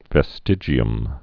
(vĕ-stĭjē-əm)